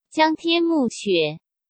Pronuncia:
Pronuncia.wav